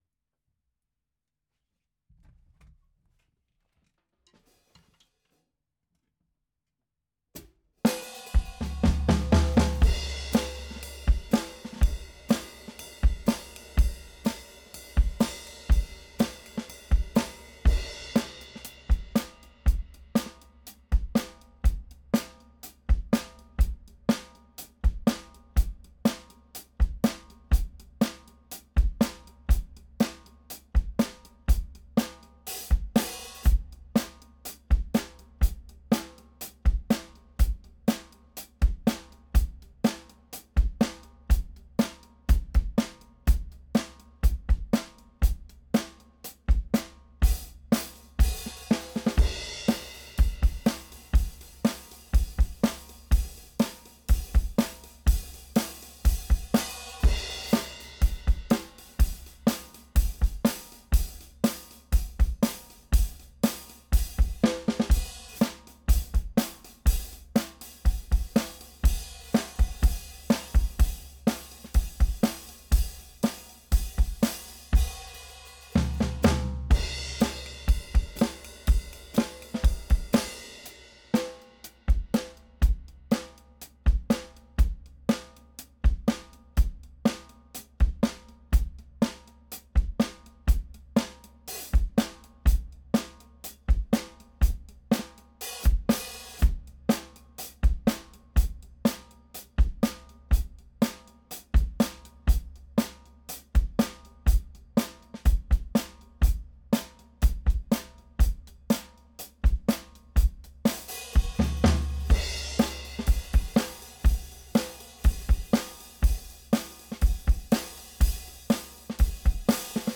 JJM studioBebel, Bielefeld